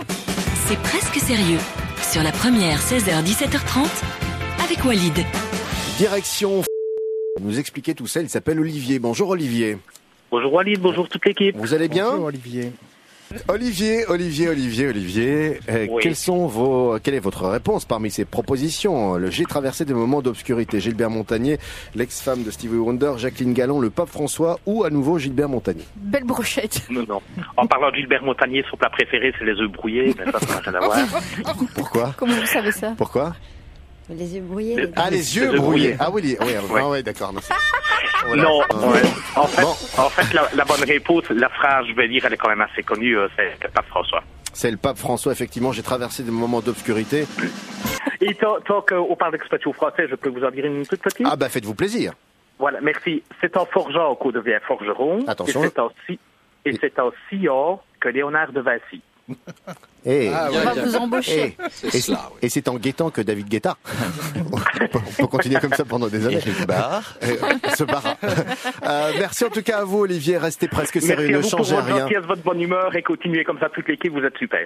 Tous les émetteurs sauf un : La Première, seule, continuait à émettre l’émission quotidienne C’est Presque Sérieux en direct.
En voici pour preuve un court extrait (“Qui a dit ?”) de cette émission d’anthologie :